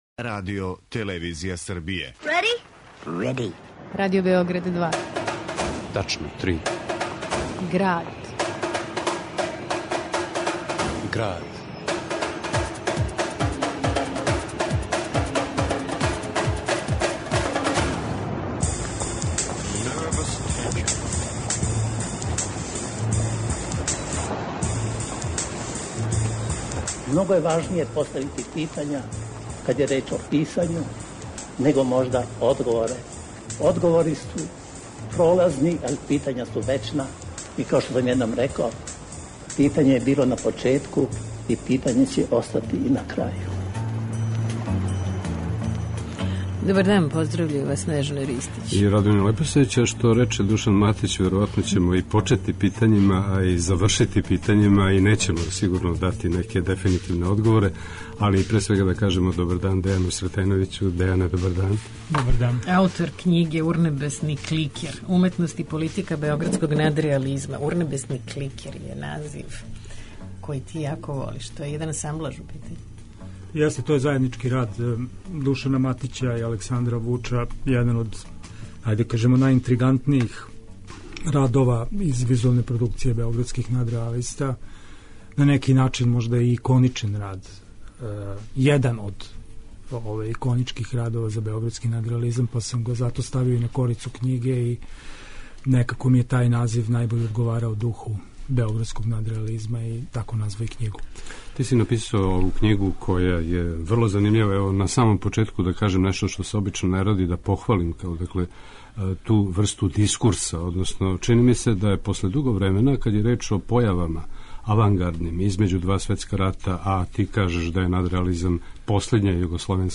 У Граду , уз архивске снимке Александра Вуча, Оскара Давича, Марка Ристића и Душана Матића